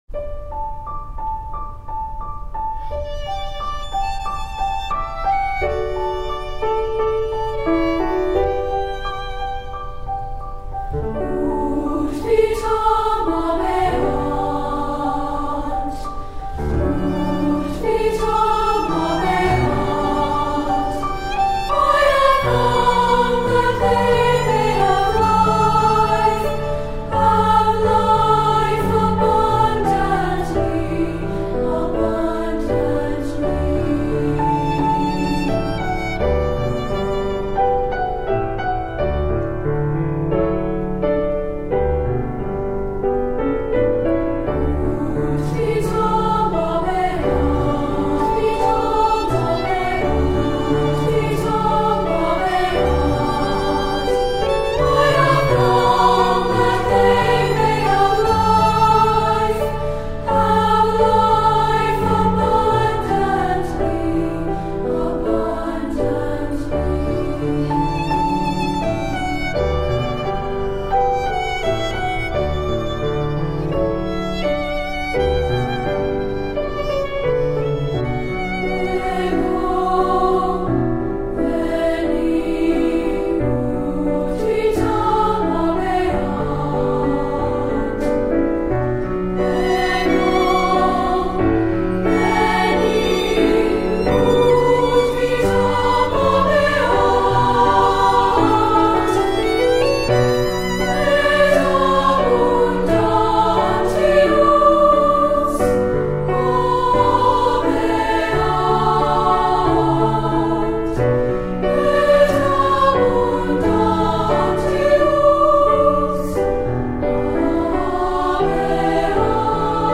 Voicing: Unison/2-Part and Piano